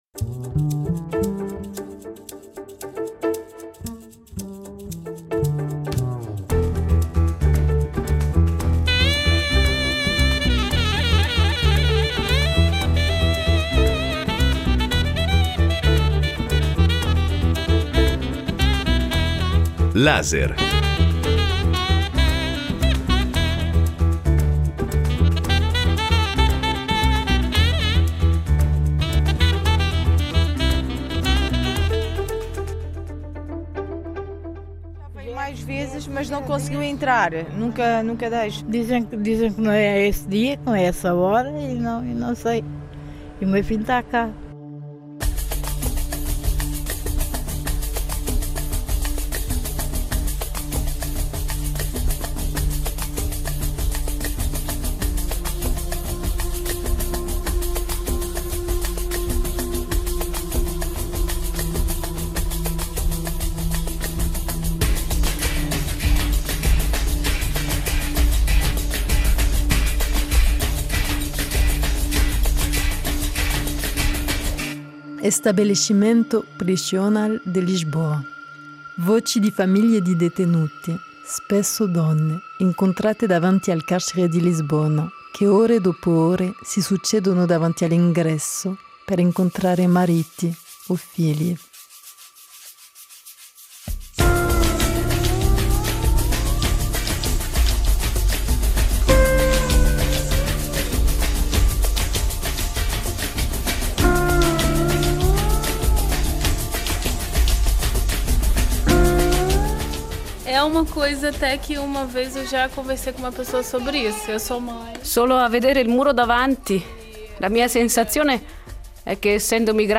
Una giornata davanti al carcere di Lisbona.
Un podcast realizzato durante un incontro Europeo sul carcere a Lisbona, nell’ambito del progetto SHARAD.